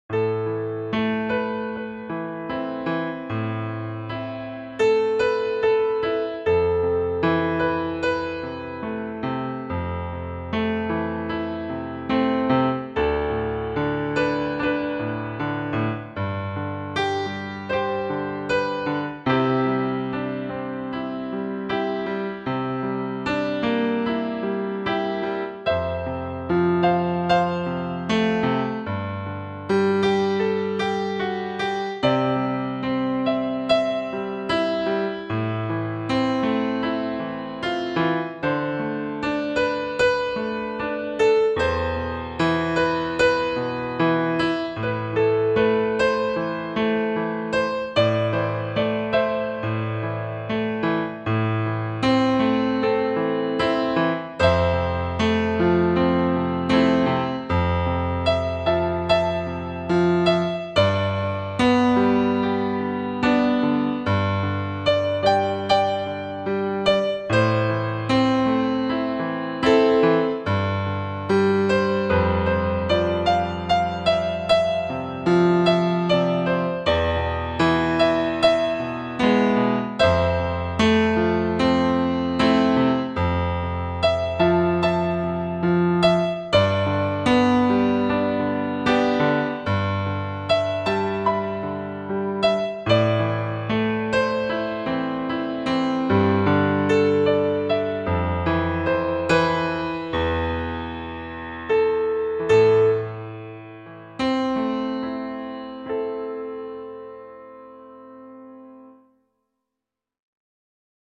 The Piano is my own work, I sampled it myself, and it's playing back on an OASYS in this demo.
For example, here's the same sound played live by me.
My Piano above has 5 with each stereo sample only stretched 1 semi tone in either direction from the root note.
grandpiano-1.mp3